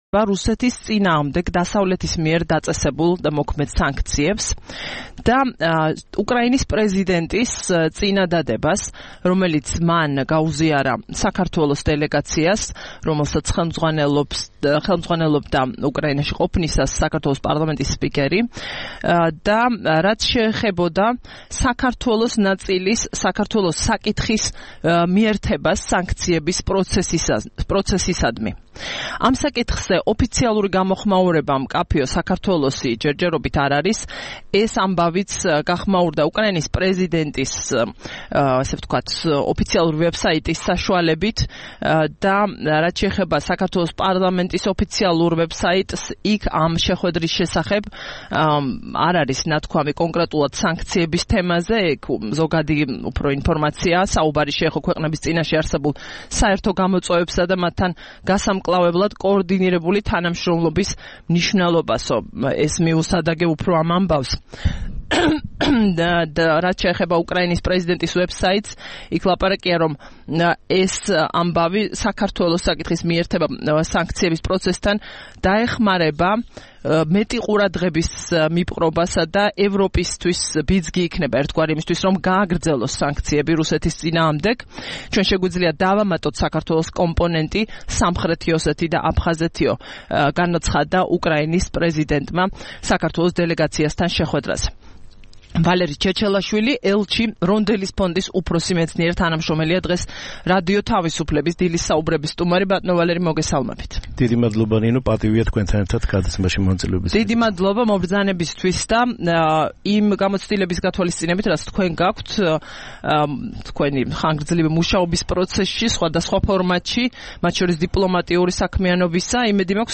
23 იანვარს რადიო თავისუფლების "დილის საუბრების" სტუმარი იყო ვალერი ჩეჩელაშვილი, ელჩი, "რონდელის ფონდის" უფროსი მეცნიერ-თანამშრომელი.